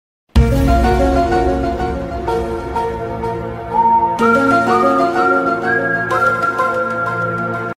Illuminati Meme Sound Effect Thanks sound effects free download